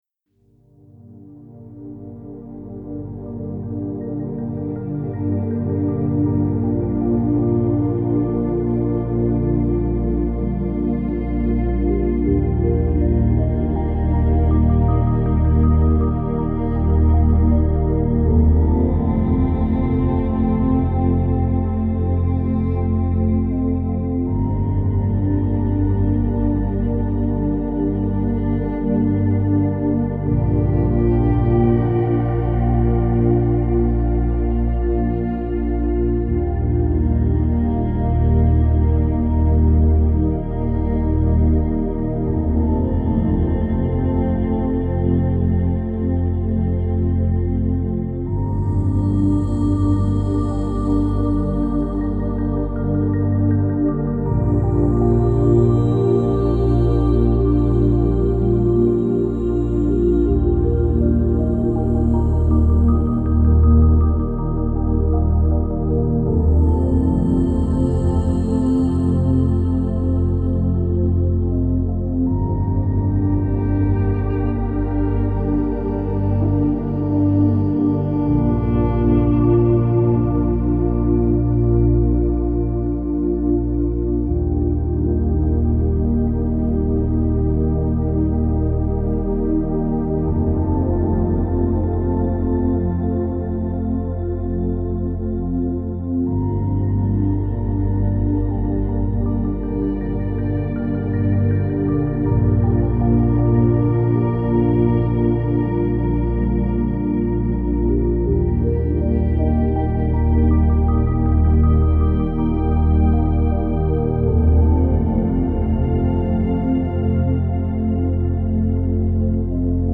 это нежная и меланхоличная композиция в жанре неоклассики